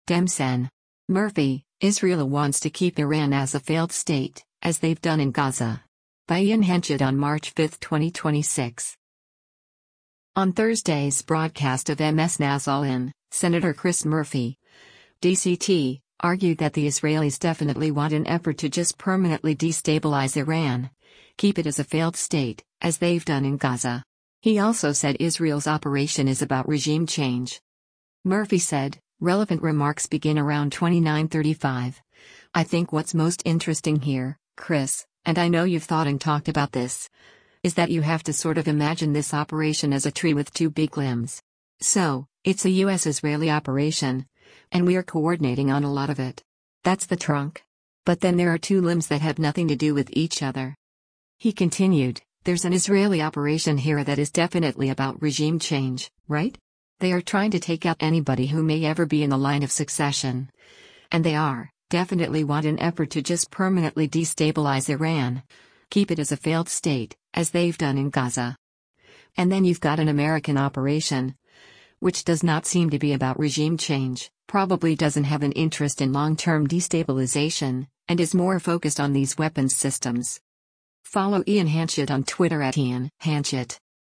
On Thursday’s broadcast of MS NOW’s “All In,” Sen. Chris Murphy (D-CT) argued that the Israelis “definitely want an effort to just permanently destabilize Iran, keep it as a failed state, as they’ve done in Gaza.” He also said Israel’s operation is about regime change.